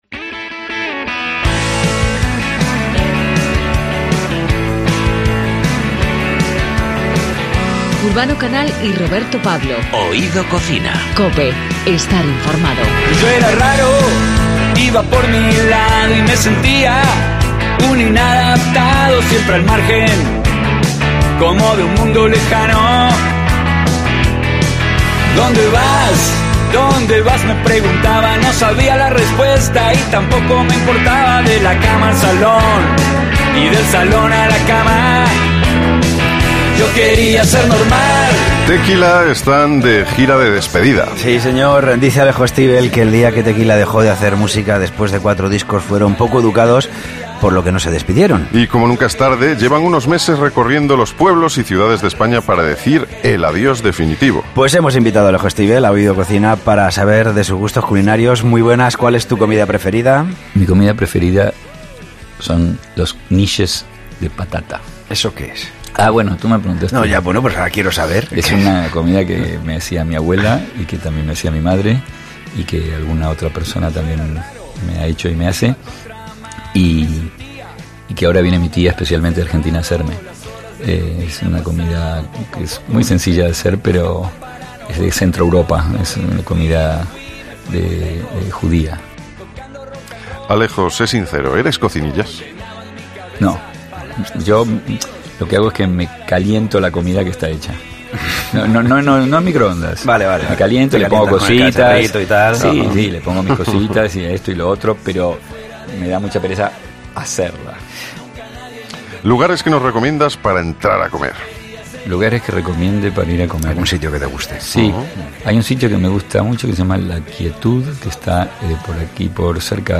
En Oído cocina hemos tenido la oportunidad de charlar con Alejo de sus gustos gastronómicos. Y aunque reconoce que no es cocinillas, sí nos deja muy claro que le gusta comer de forma sana y responsable, pero de vez en cuando se intoxica con un buen menú cuyo producto principal es la carne.